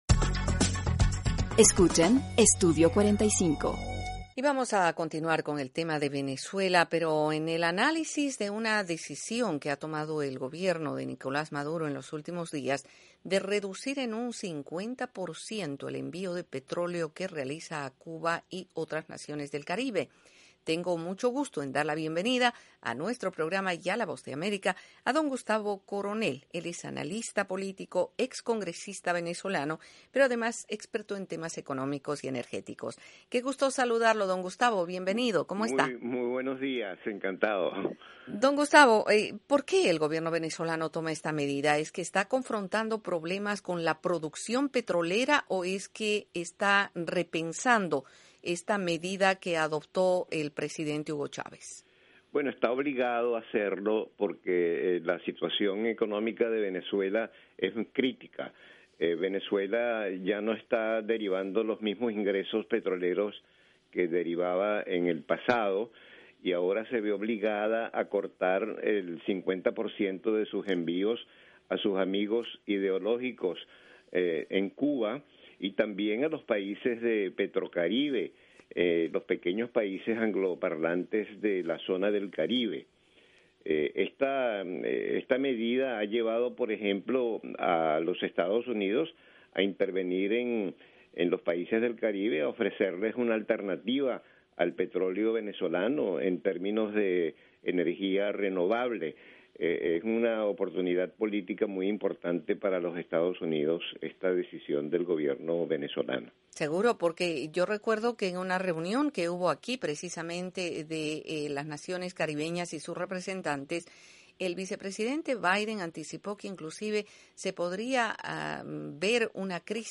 Entrevista con Gustavo Coronel
La decisión del gobierno de Venezuela de reducir los envíos de petróleo a Cuba y a las naciones de Petrocaribe se analiza en esta entrevista de la Voz de América con el analista y ex congresista venezolano, Gustavo Coronel.